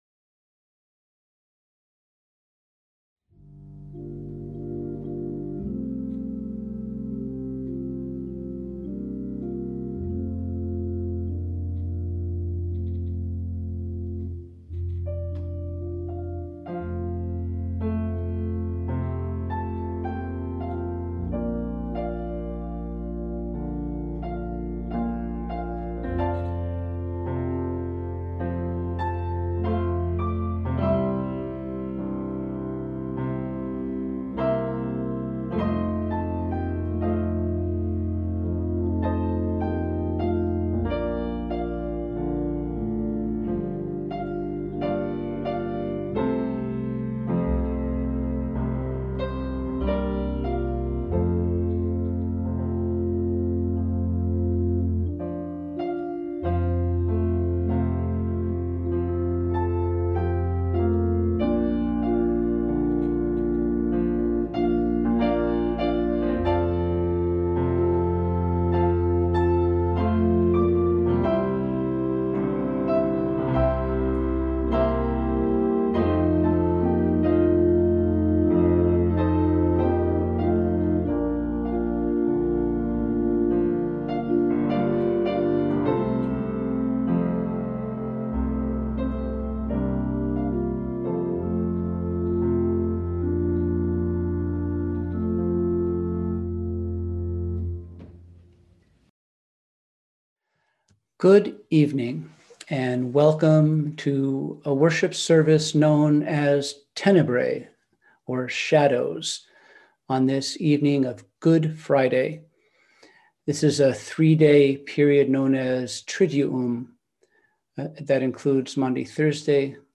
We held a Good Friday Tenebrae on Friday, April 2, 2021 at 7pm.